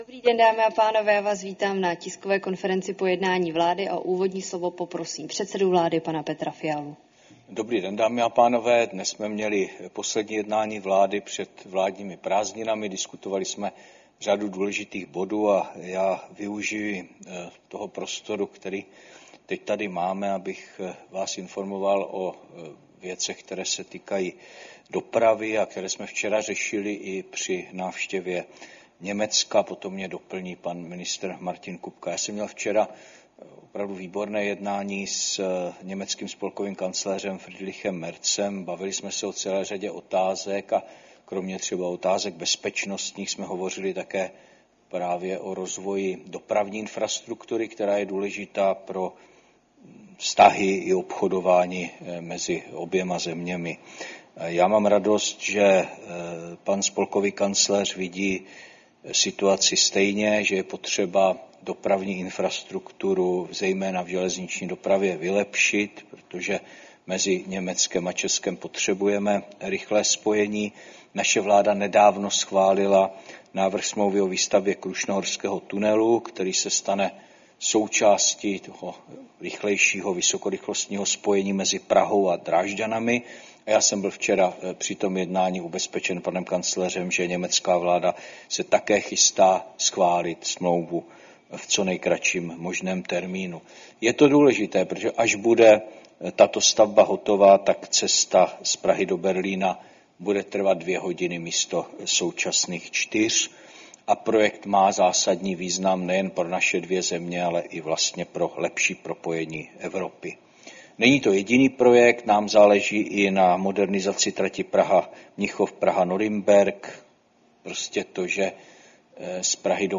Tisková konference po jednání vlády, 23. července 2025